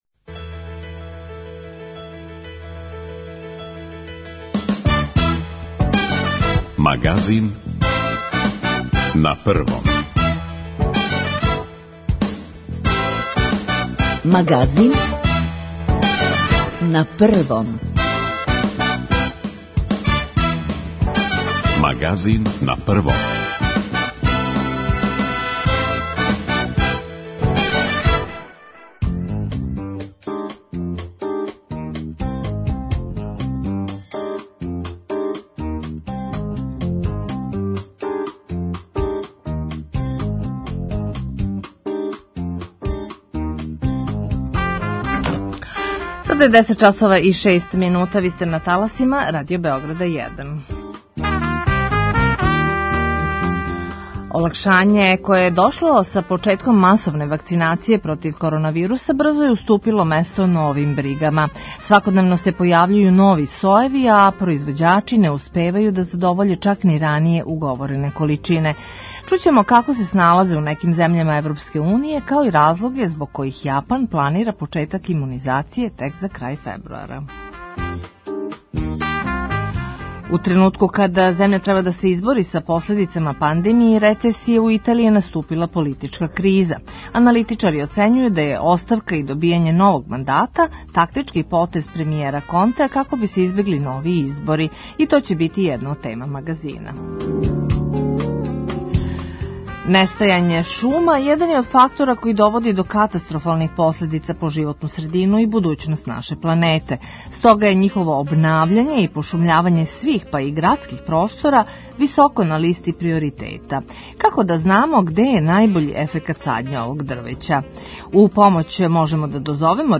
Многи страхују да би то могло да доведе у питање одржавање Олимпијских игара. преузми : 28.94 MB Магазин на Првом Autor: разни аутори Животне теме, атрактивни гости, добро расположење - анализа актуелних дешавања, вести из земље и света.